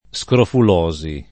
scrofulosi [ S kroful 0@ i ]